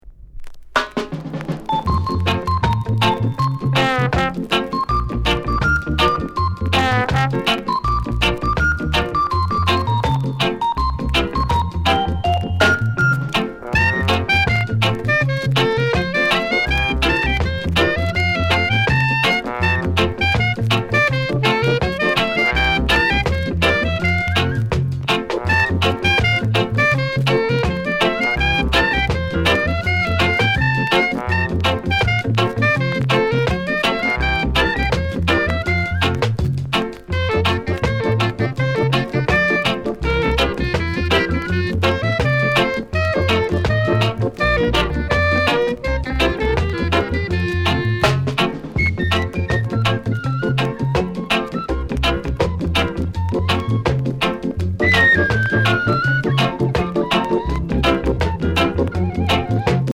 NICE ROCKSTEADY